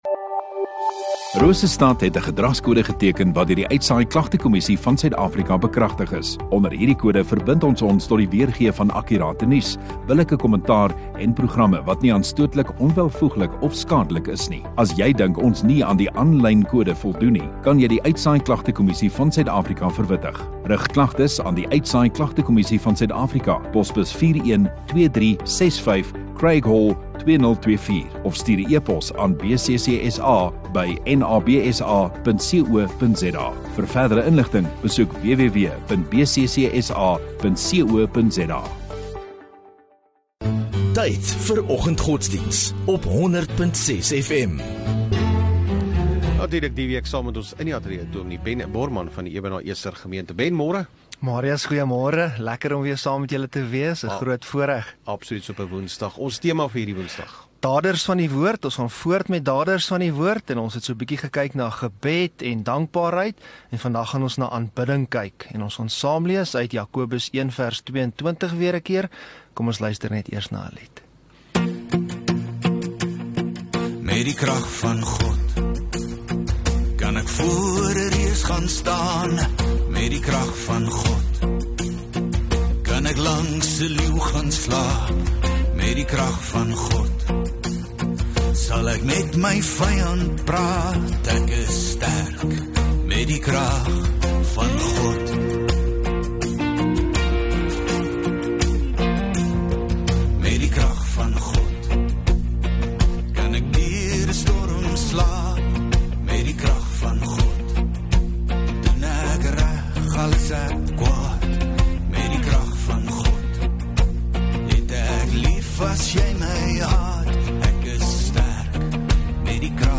View Promo Continue Install Rosestad Godsdiens 15 Jan Woensdag Oggenddiens